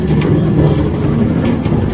minecart